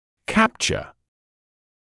[‘kæpʧə][‘кэпчэ]фиксировать, записывать (с помщью соответствующей записывающей аппаратуры); захватывать